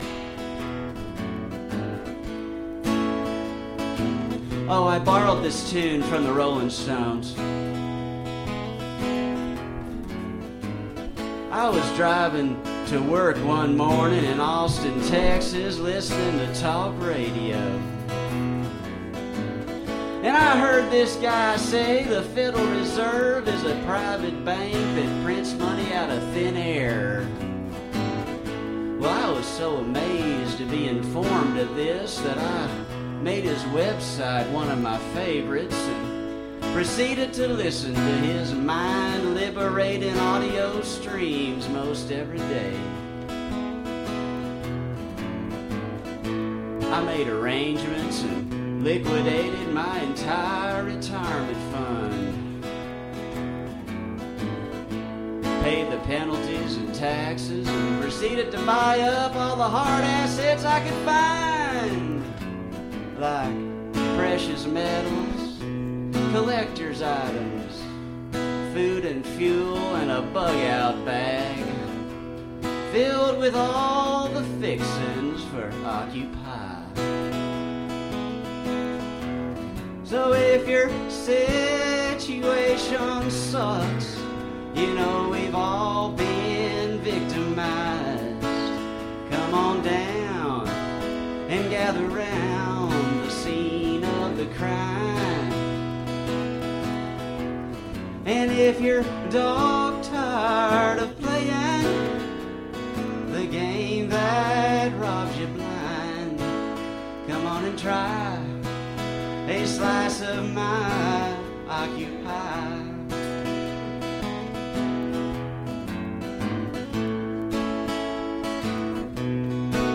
recorded live on the Liberty Stage at
Brave New Books, Austin, TX, 1/25/2012
Capo 2, Play E